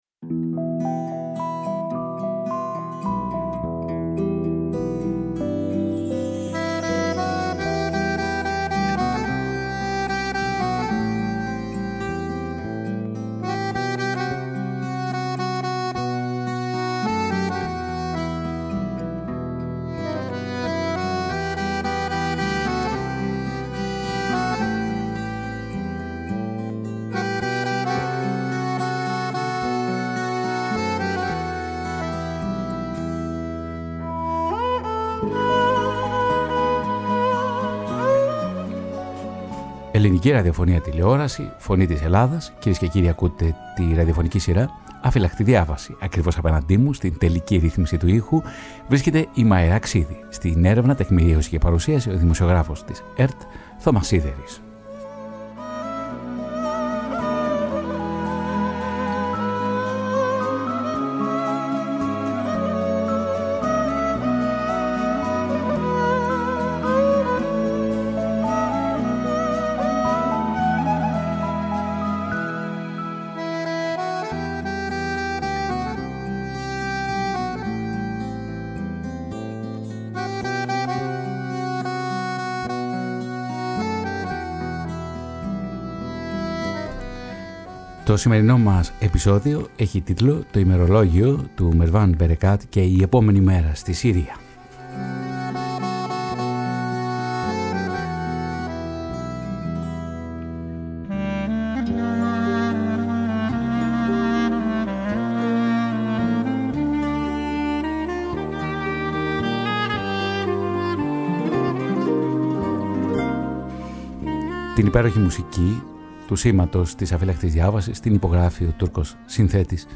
Ντοκιμαντέρ